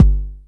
DrKick60.WAV